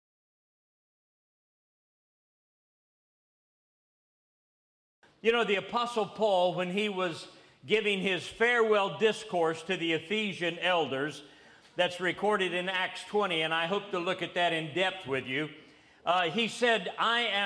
This message was delivered to a gathering of pastors in Ghana, Africa in August of 2009.